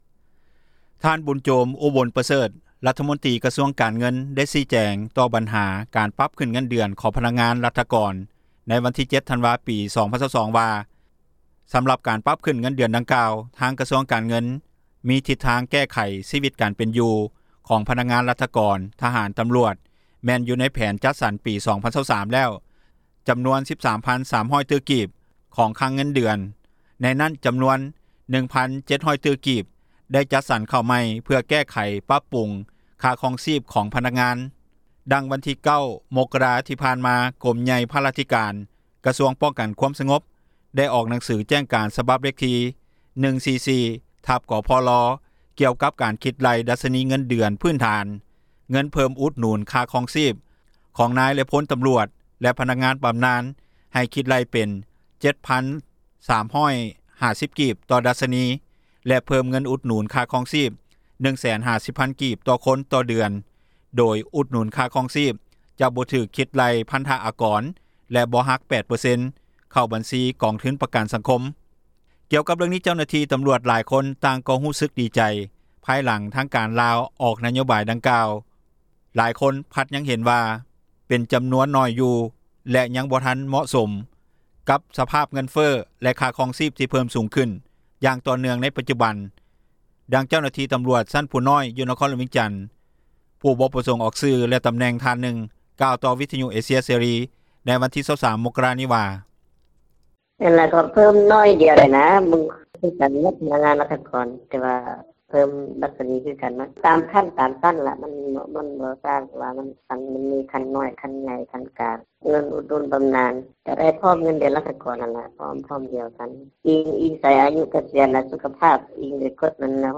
ດັ່ງເຈົ້າໜ້າທີ່ ຕຳຣວດຊັ້ນຜູ້ນ້ອຍ ຢູ່ນະຄອນຫລວງວຽງຈັນ ຜູ້ບໍ່ປະສົງອອກຊື່ ແລະ ຕຳແໜ່ງທ່ານນຶ່ງ ກ່າວຕໍ່ວິທຍຸ ເອເຊັຽ ເສຣີ ໃນວັນທີ 23 ມົກຣາ 2023 ນີ້ວ່າ:
ດັ່ງເຈົ້າໜ້າທີ່ ຕຳຣວດ ຢູ່ແຂວງເຊກອງ ຜູູ້ບໍ່ປະສົງອອກຊື່ ແລະ ຕຳແໜ່ງ ທ່ານນຶ່ງ ກ່າວຕໍ່ວິທຍຸ ເອເຊັຽ ເສຣີໃນມື້ດຽວກັນນີ້ວ່າ: